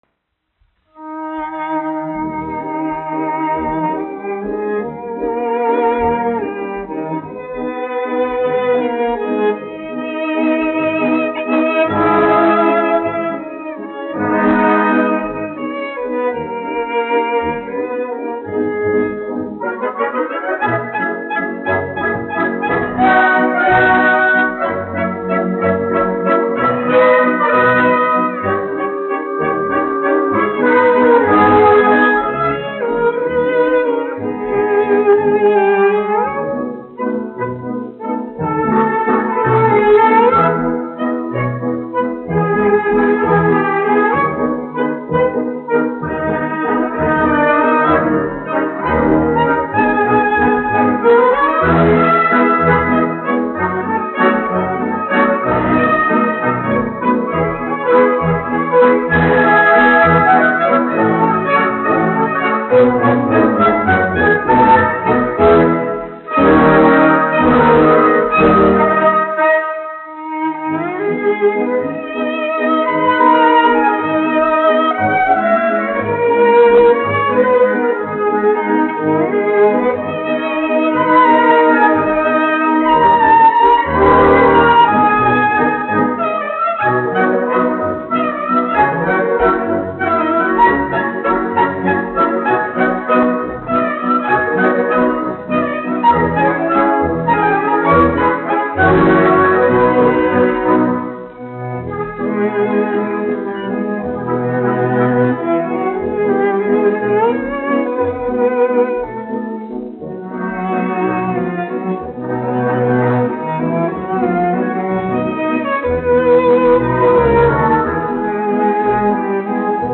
1 skpl. : analogs, 78 apgr/min, mono ; 25 cm
Valši
Stīgu orķestra mūzika
Skaņuplate
Latvijas vēsturiskie šellaka skaņuplašu ieraksti (Kolekcija)